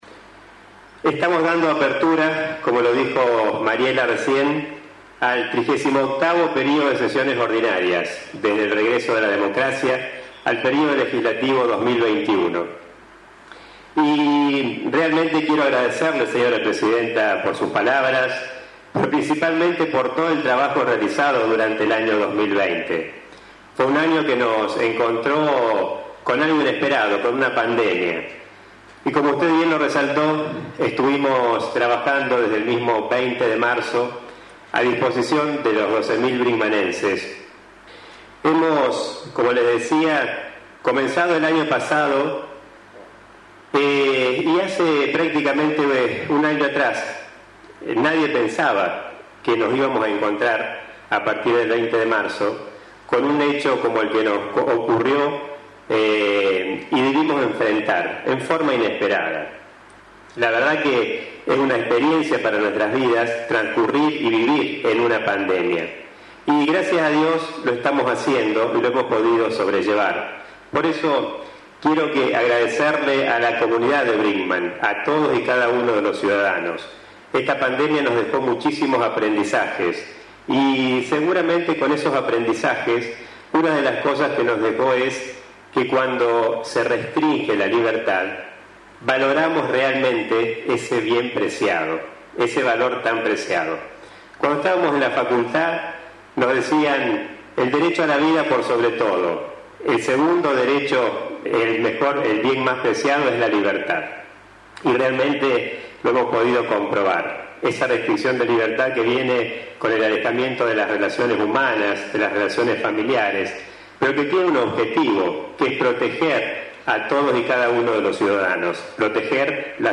En el Teatrillo Municipal y con una reducida concurrencia, solo limitada a los funcionarios municipales, autoridades policiales, de Bomberos, el radicalismo local, se realizó el Acto Apertura del Período de Sesiones Ordinarias del Concejo Deliberante de Brinkmann.
Luego el intendente municipal de Brinkmann dejó su Mensaje Anual para este 2021, donde repasó a manera de balance lo realizado durante el 2020 y proyectó las realizaciones, programas y proyectos para este año.